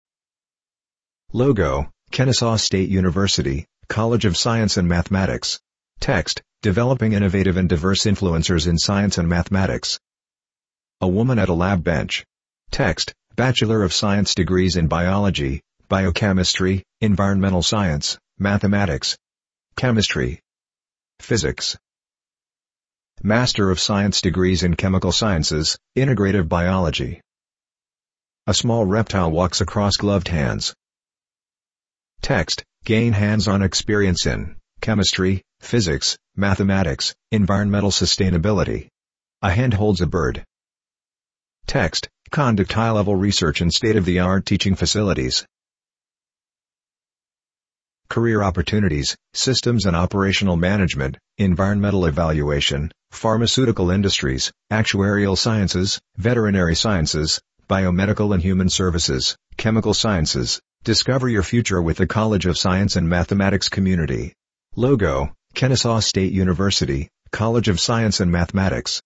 Audio description for the embedded video